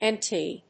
エヌディーティー